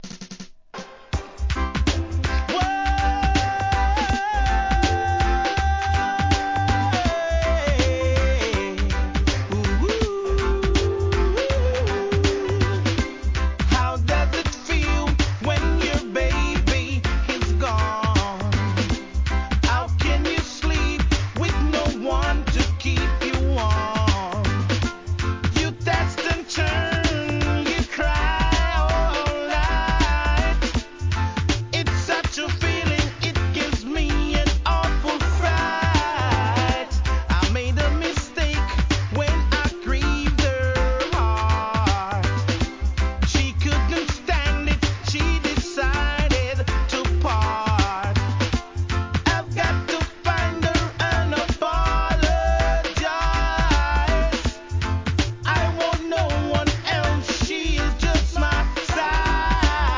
REGGAE
一曲目から伸びやかなヴォーカルで聴かせる1992年作品!!